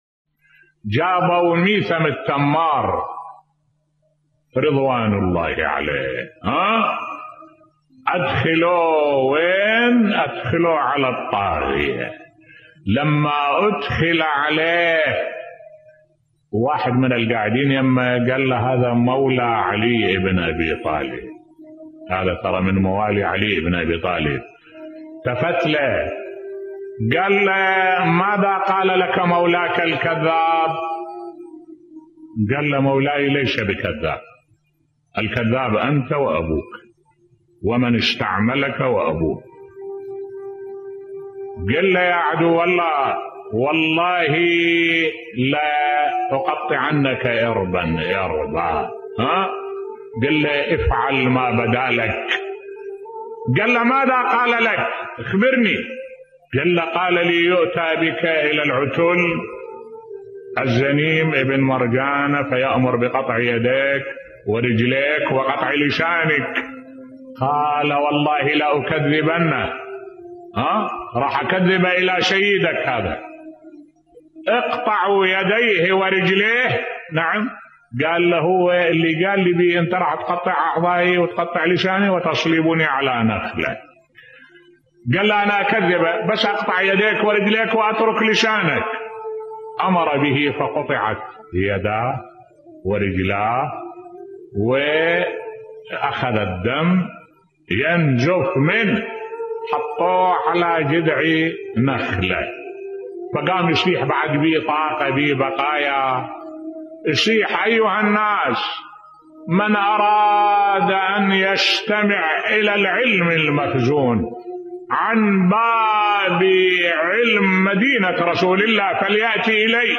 ملف صوتی شجاعة و شهادة ميثم التمار (رض) بصوت الشيخ الدكتور أحمد الوائلي